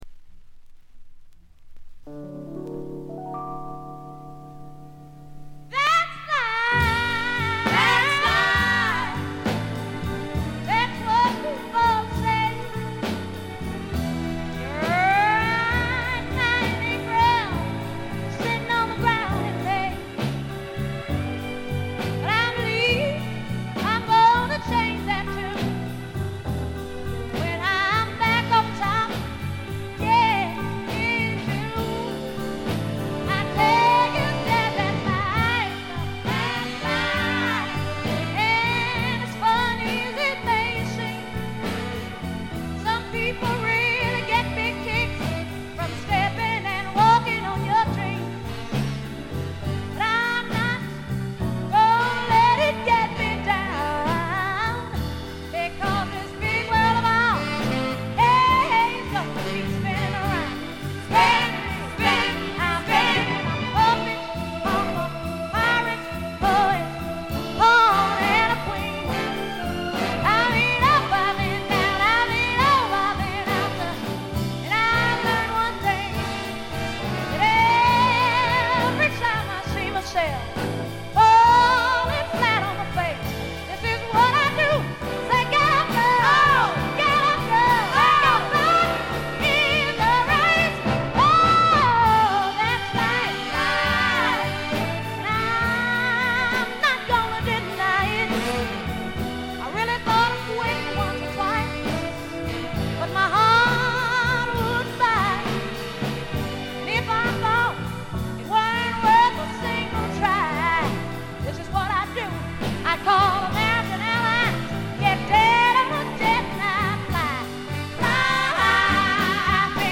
ほとんどノイズ感無し。
試聴曲は現品からの取り込み音源です。
vocals, piano